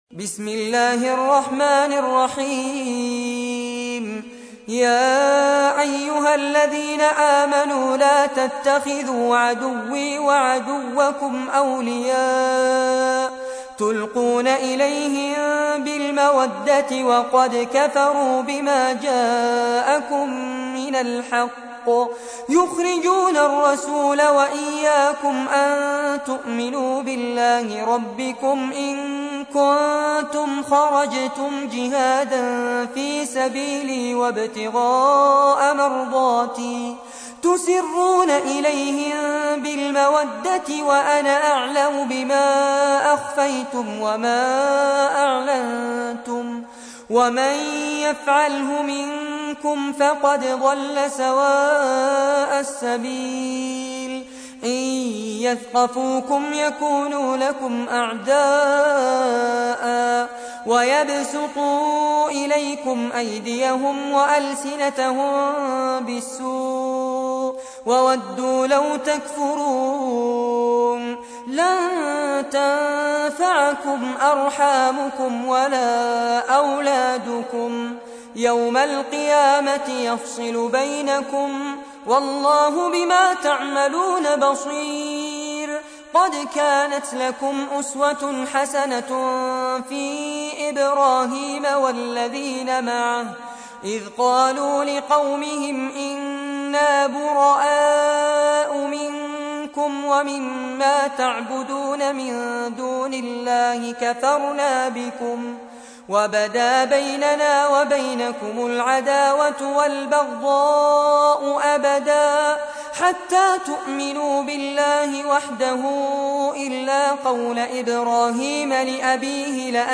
تحميل : 60. سورة الممتحنة / القارئ فارس عباد / القرآن الكريم / موقع يا حسين